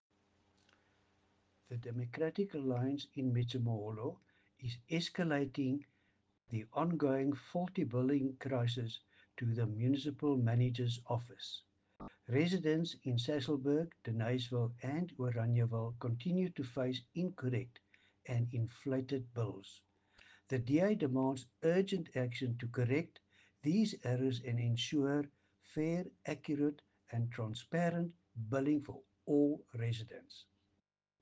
Afrikaans soundbites by Cllr Louis van Heerden and